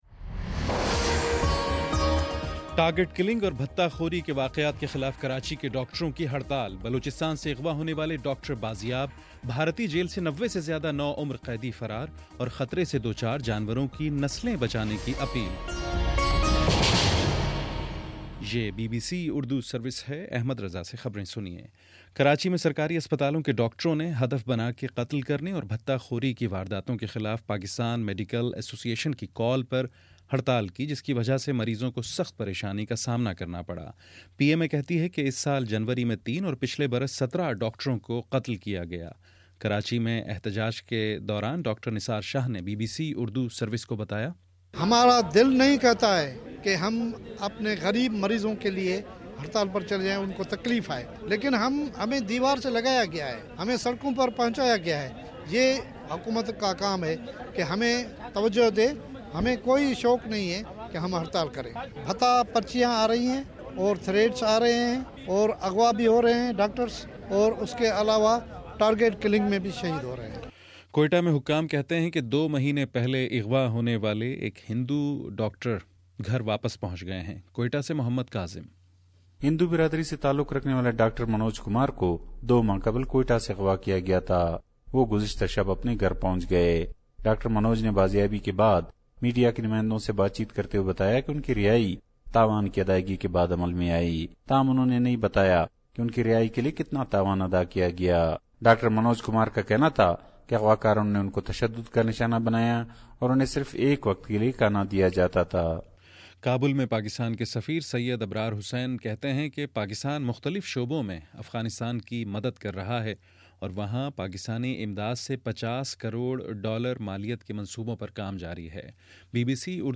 فروری02: شام سات بجے کا نیوز بُلیٹن